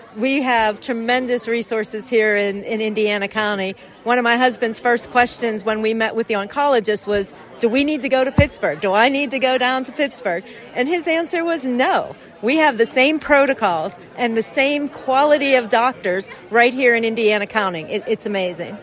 The IRMC Healthcare Foundation kicked off the 20th annual Love of Life fundraising campaign, benefitting the Women’s Imaging Center, Thursday night.